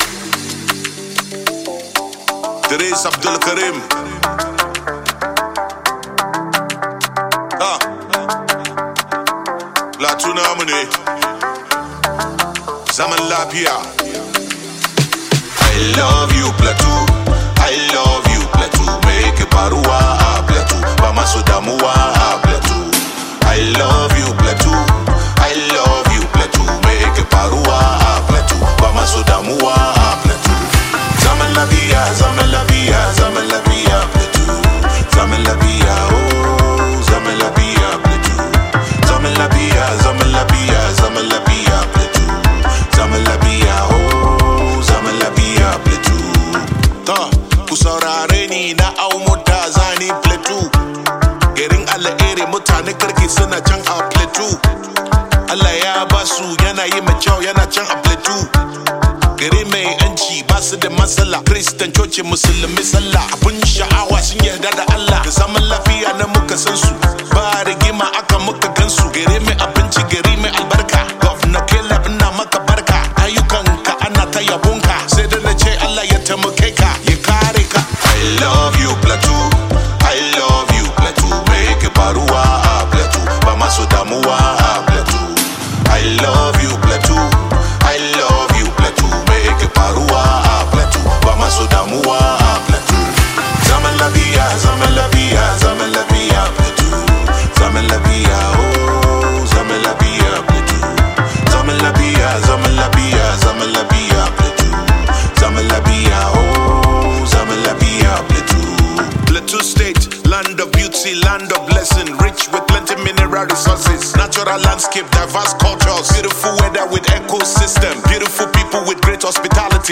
rapper
combines emotional lyrics with rich cultural sounds